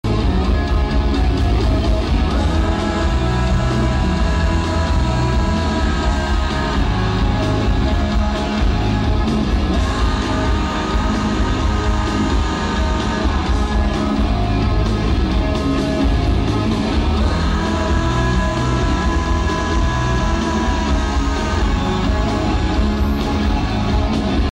Audio : 8/10 Un bon son provemant de la video.